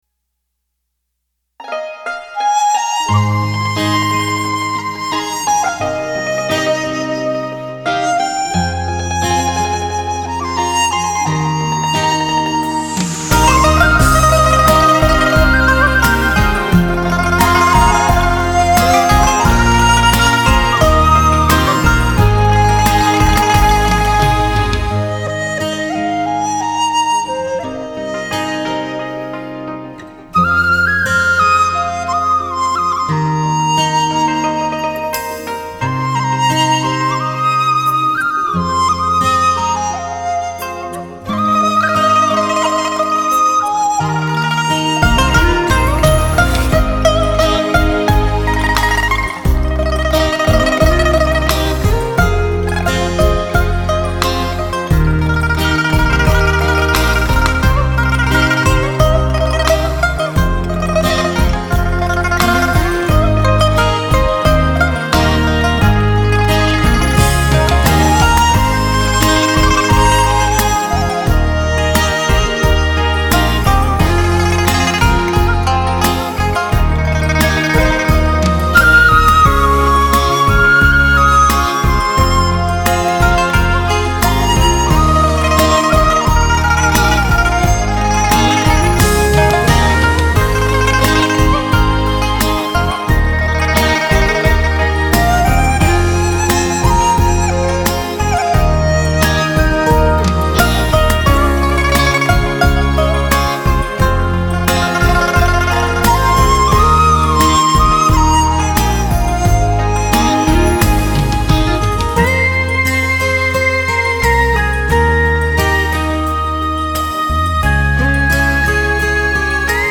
音乐类型: 纯音民乐
民乐悠扬缠绵，委婉动听，是中国文化的瑰宝，在繁忙喧嚣的都市， 品一段民乐妙韵，带你进人一个沁人心脾的世外桃源。